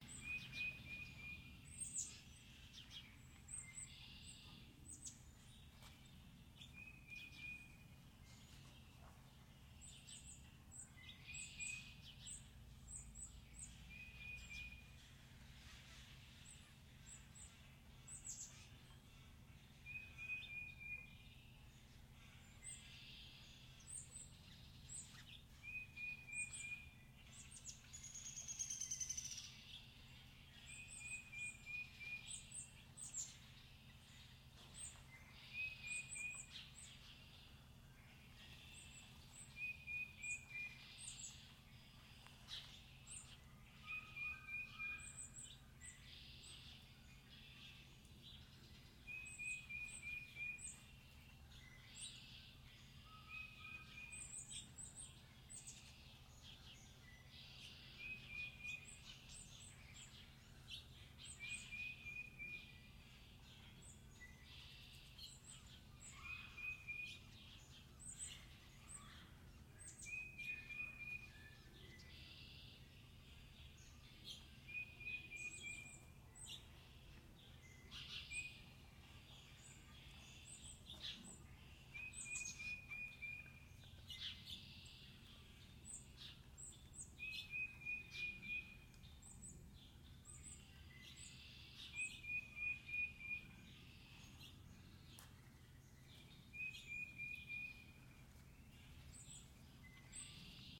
Bird Sanctuary Nj Loop.ogg